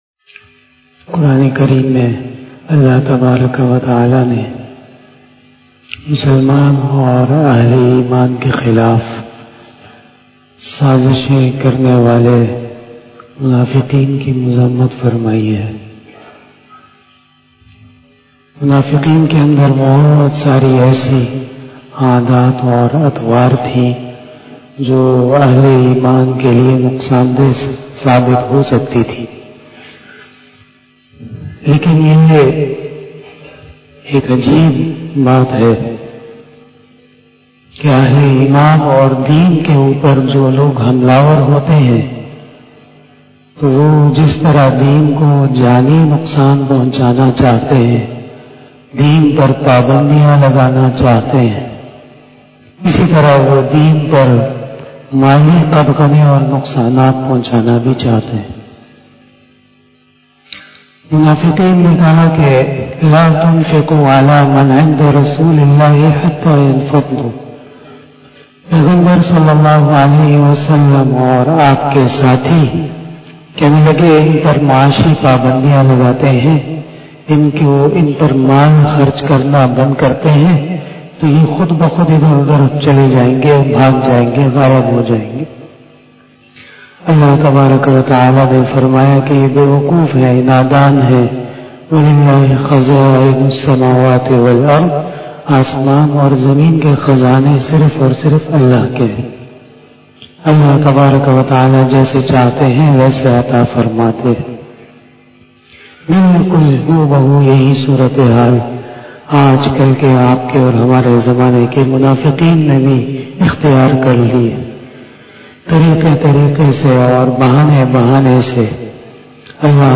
002 After Fajar Namaz Bayan 05 January 2021 ( 20 Jamadil Uola 1442HJ)